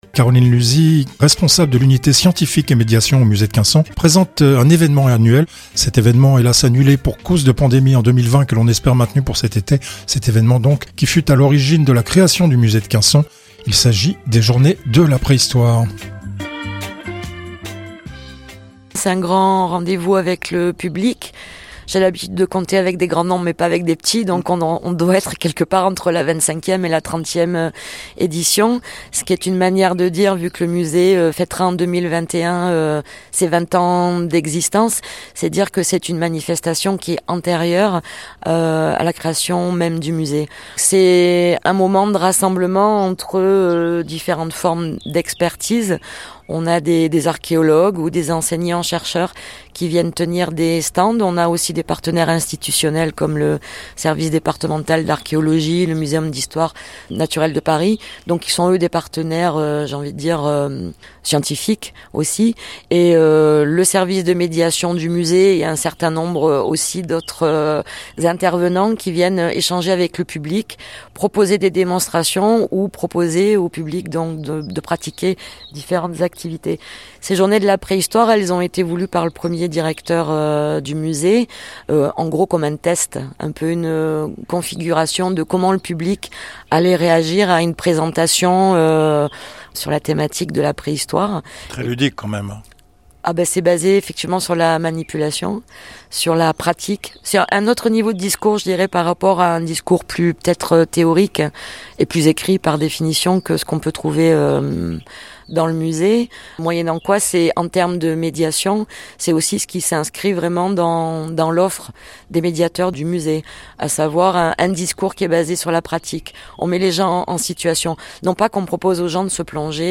Extrait d’une interview